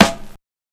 SNARE 90S 1.wav